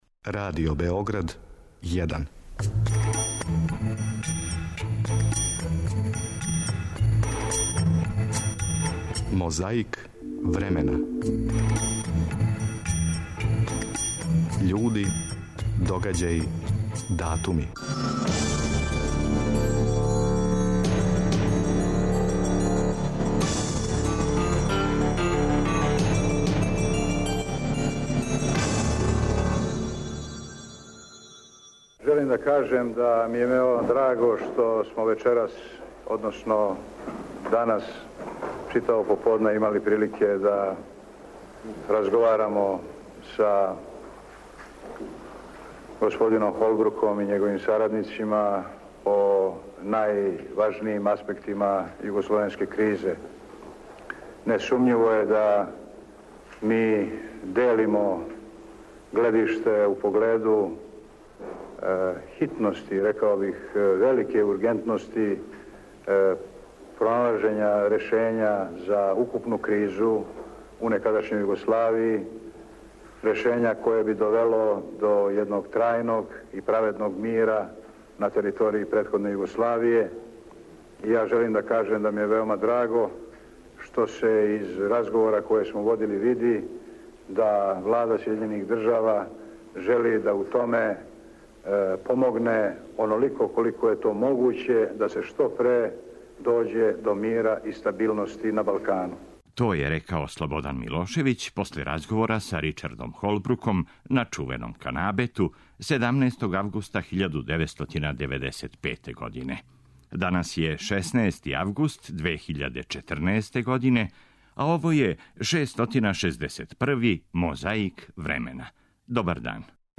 Podsećamo da je rezultat bio nerešen, 0:0, a vi ćete čuti kako je o tom događaju izveštavala Hrvatska radio-televizija.
Tom prilikom drug Tito i drug Hruščov razmenili su zdravice.
Prisutnima su se obratili i gost i domaćin.